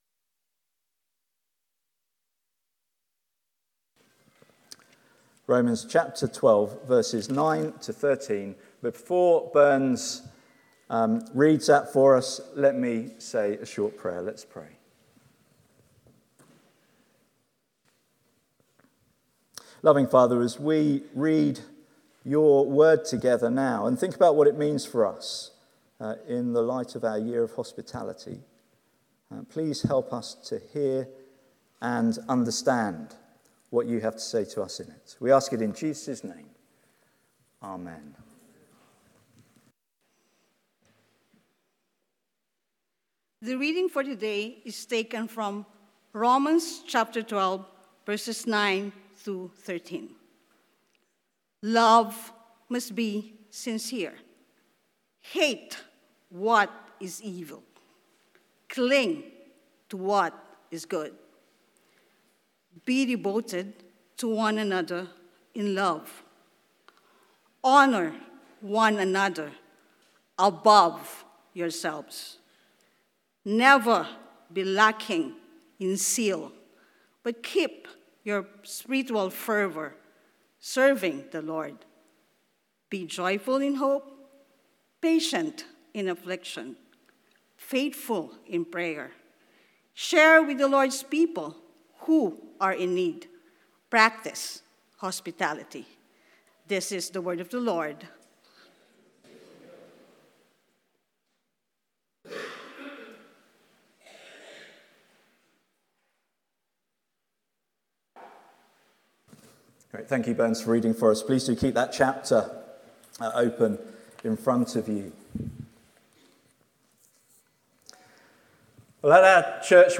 Passage: Romans 12:9-13 Service Type: Sunday Morning